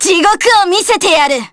Yanne_L-Vox_Skill3_jp.wav